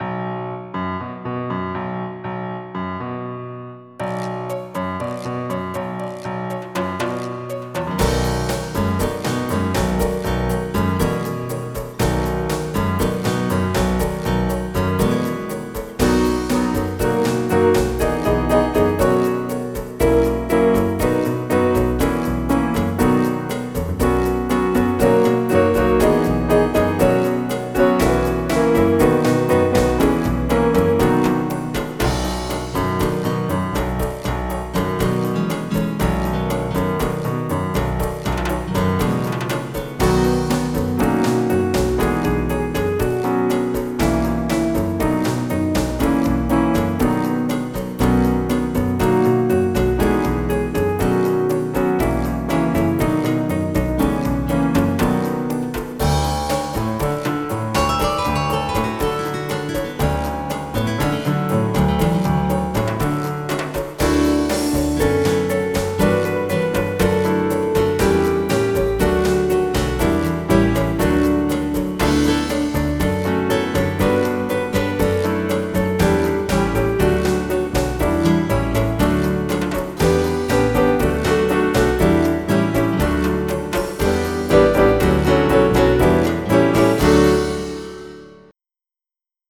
MIDI Music File
2 channels